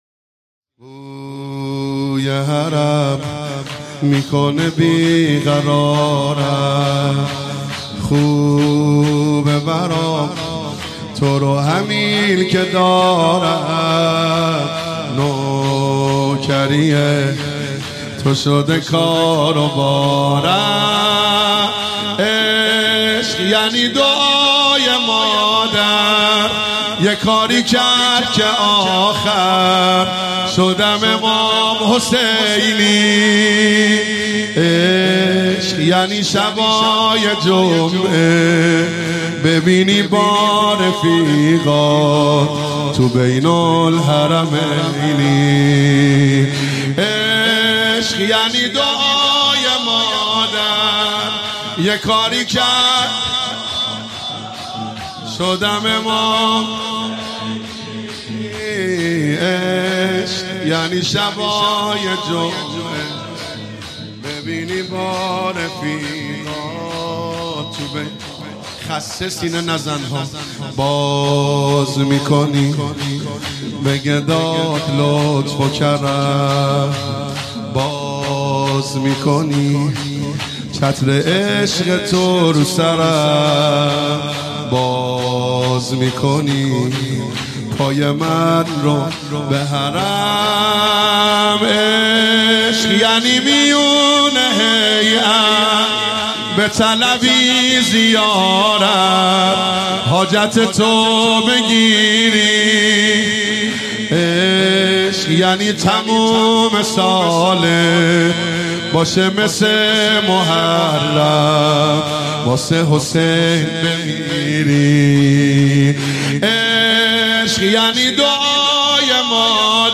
شب هفتم محرم97 هیئت یا فاطمه الزهرا (س) بابل
بوی حرم میکنه بیقرارم - شور